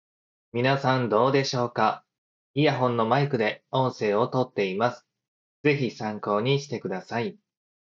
私の声も相手には、こもった感じとの事！
実際のマイク音質！
通話時の音質は、こもった感じ・・・
実際のマイク音質はこんな感じです。